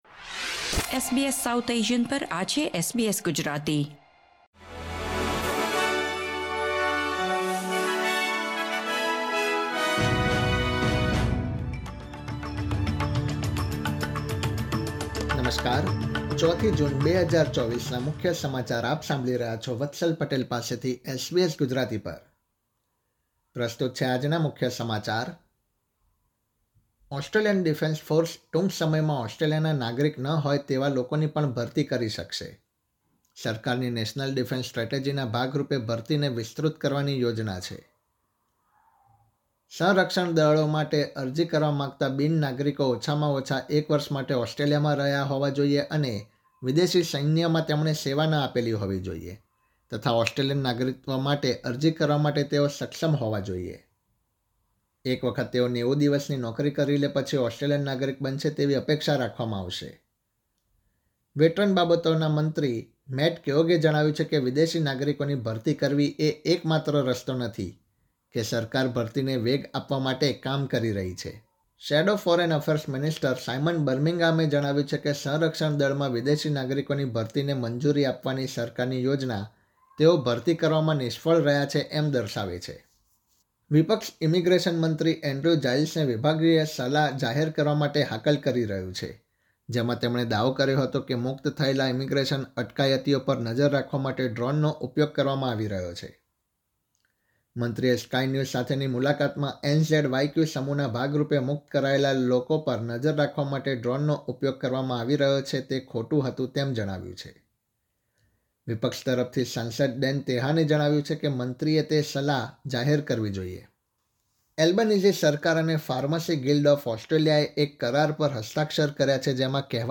SBS Gujarati News Bulletin 4 June 2024